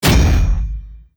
academic_newskill_alfredodrill_02_clank_01.ogg